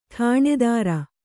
♪ ṭhāṇedāra